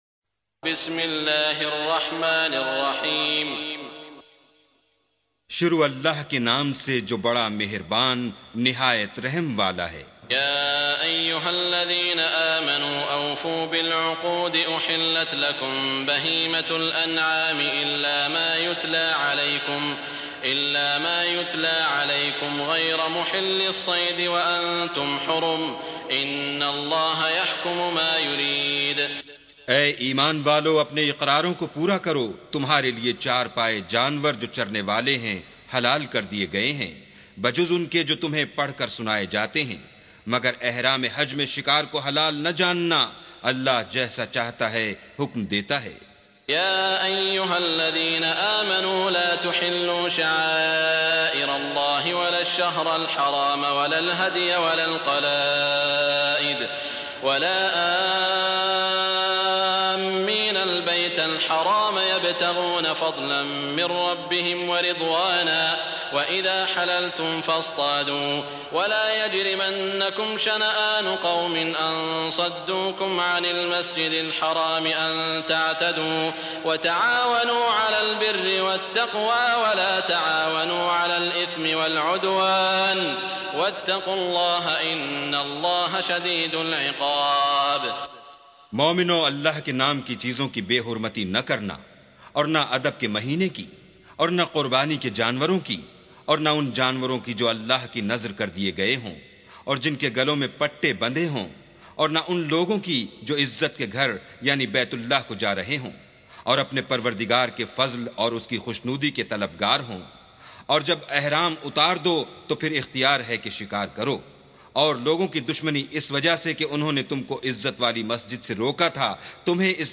سُورَةُ المَائـِدَةِ بصوت الشيخ السديس والشريم مترجم إلى الاردو